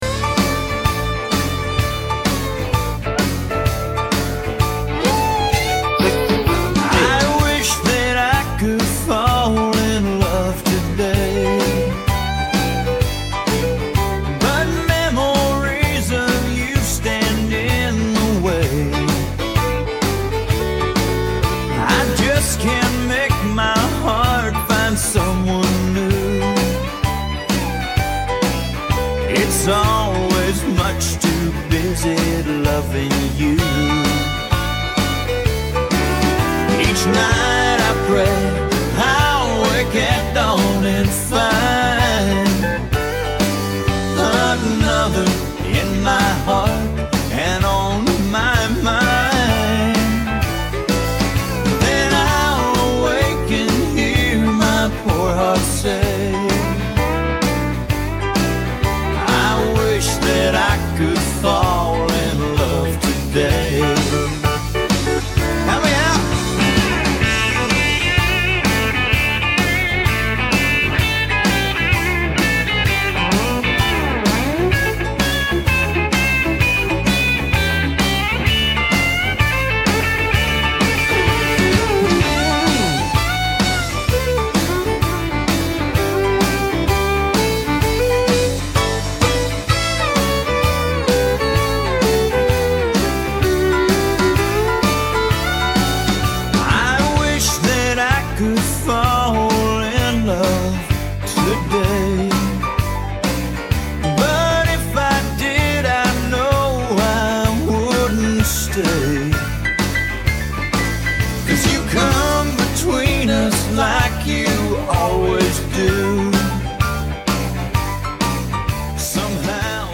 128 BPM